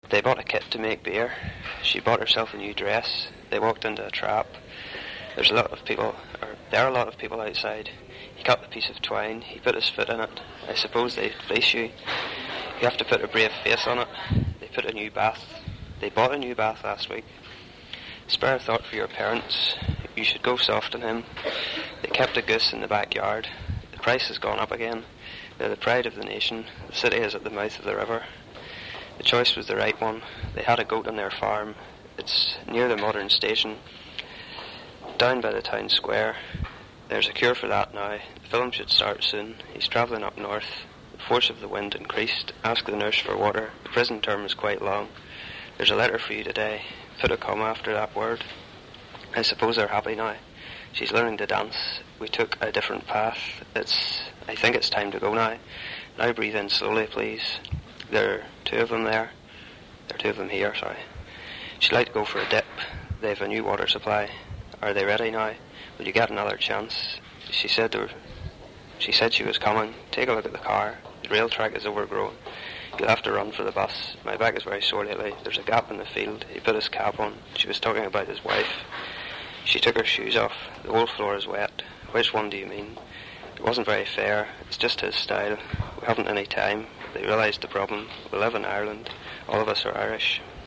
Belfast English
Belfast accent, young male
Belfast_Male_c20.mp3